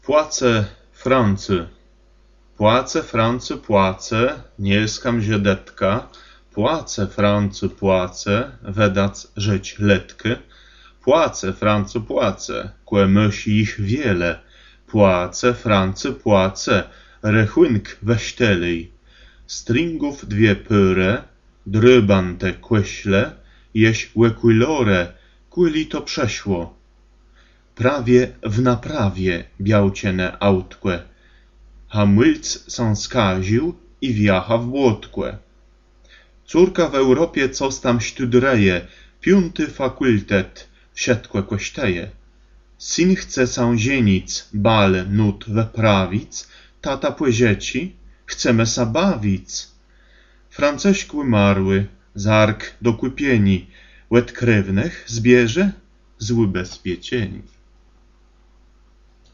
Piosenka kabaretowa